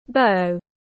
Cái cung tiếng anh gọi là bow, phiên âm tiếng anh đọc là /baʊ/
Bow /baʊ/